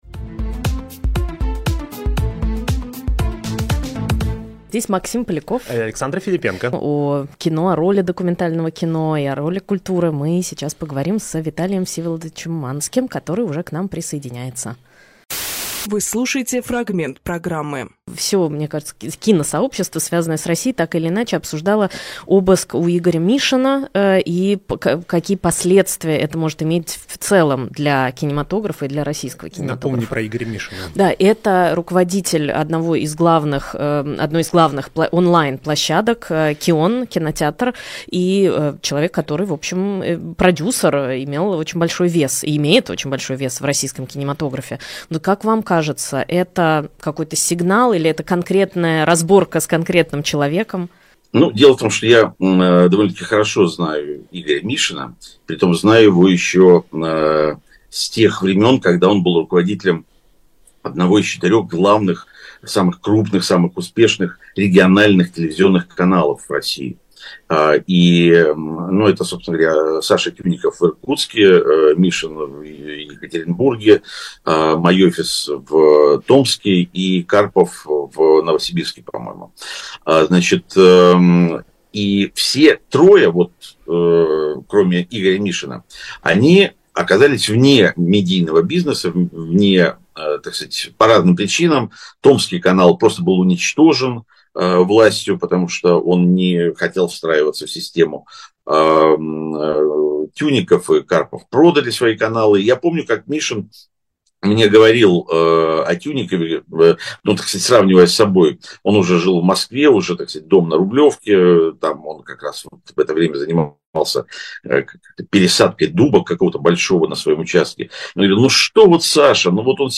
Фрагмент эфира от 24.11.24